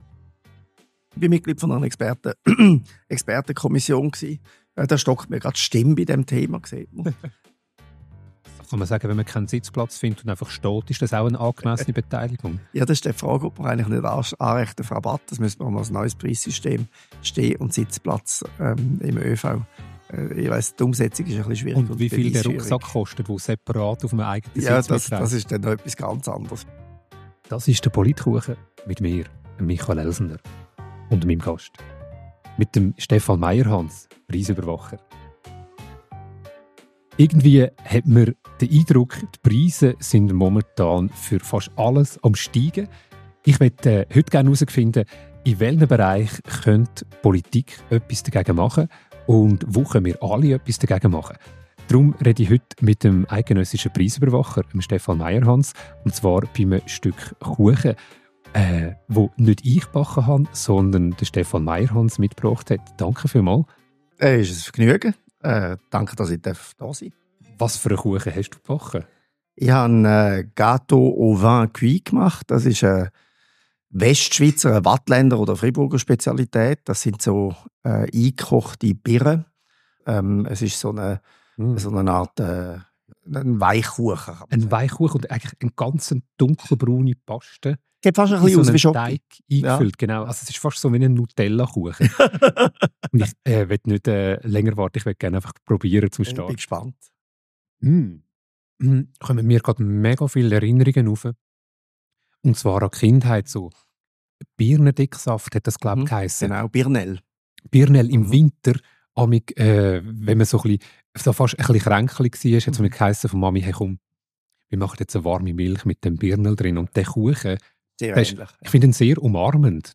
Beschreibung vor 10 Monaten Wie bringen wir das Parlament dazu, für tiefere Medikamenten-Preise zu sorgen? Satiriker & Politikwissenschafter Michael Elsener redet mit Preisüberwacher Stefan Meierhans darüber, wie der Preisüberwacher am Verhandlungstisch Firmen, Verbände und Institutionen dazu bringt, zu hohe Preise zu reduzieren.